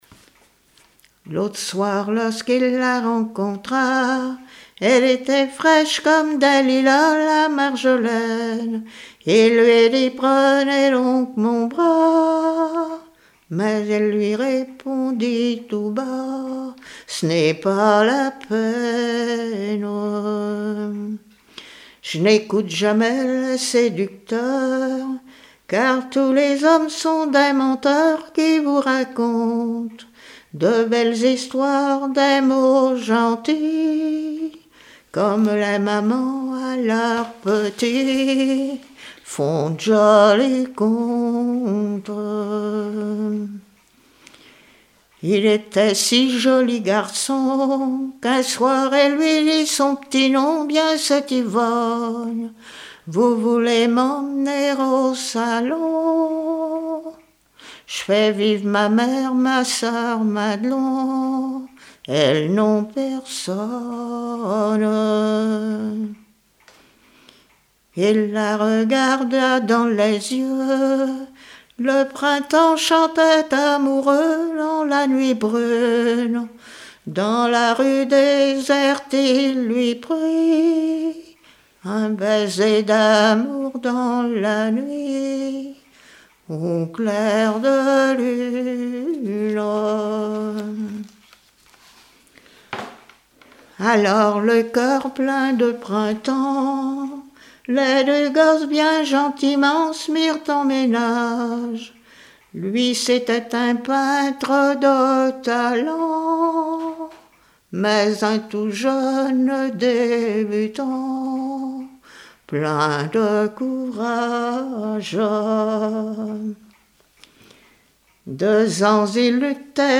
Genre strophique
chansons de variété et traditionnelles
Pièce musicale inédite